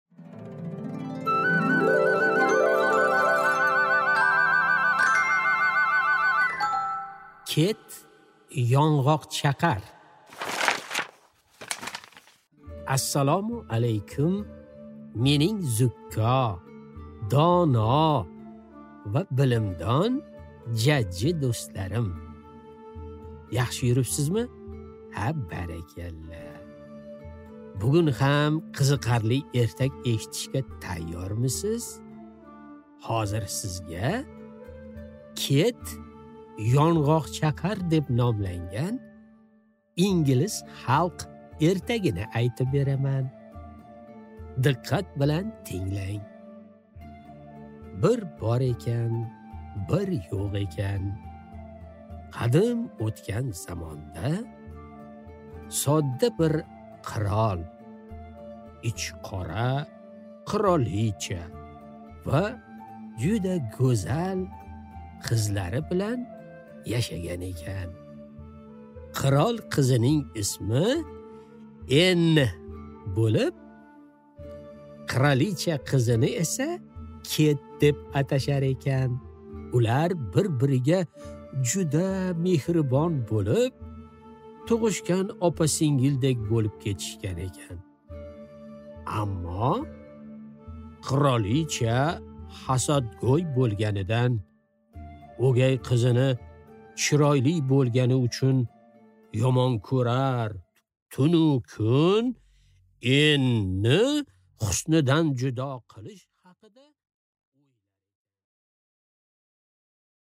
Аудиокнига Ket yong’oqchaqar